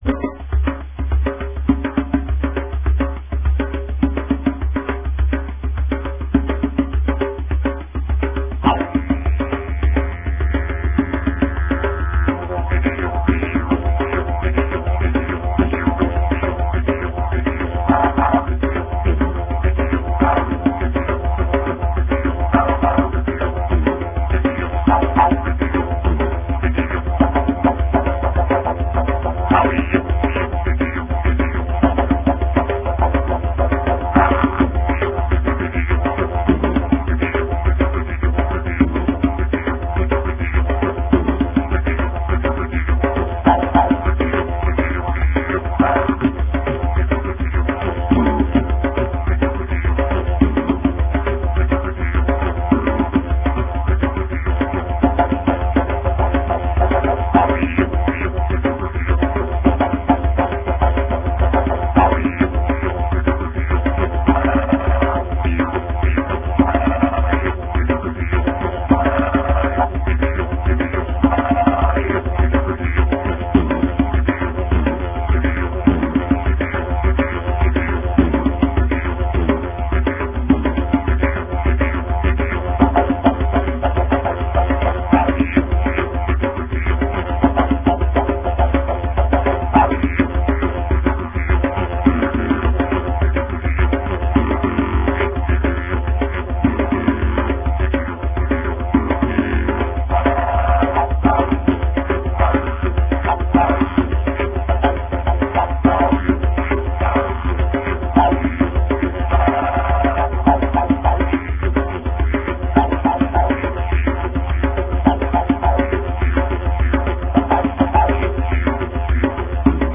Non-traditional Didjeridu